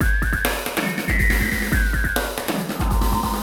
E Kit 34.wav